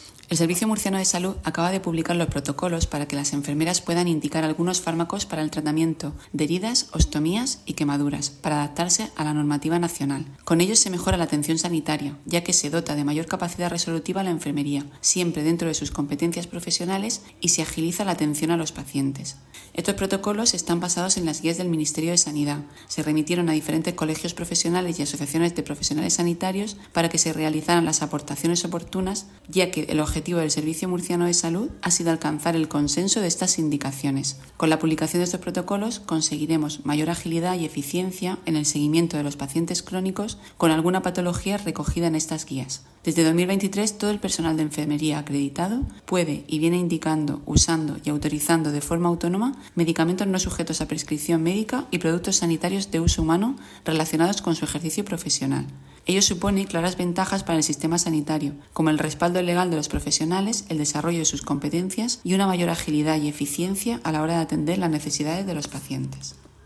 Declaraciones de la gerente del SMS, Isabel Ayala, sobre los nuevos protocolos para indicación de fármacos por el personal de Enfermería